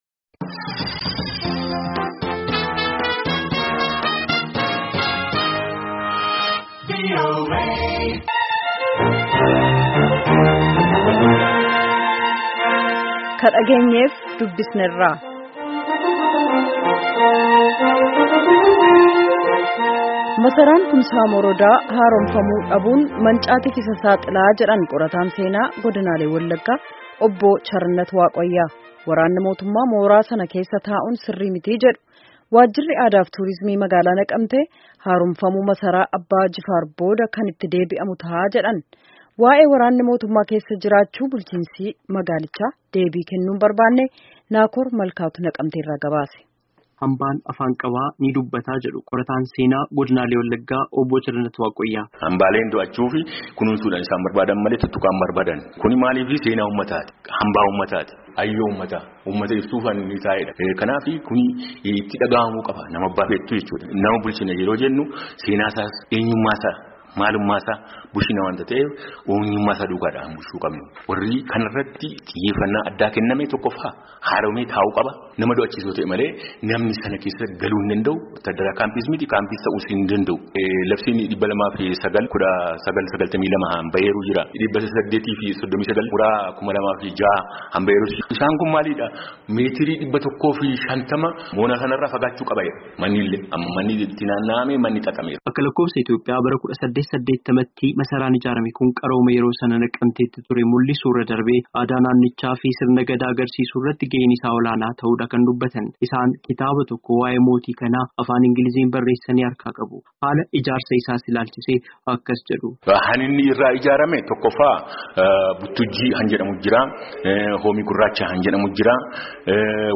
Gabaasaa